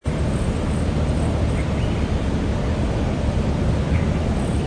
ambience_planetscape_forest.wav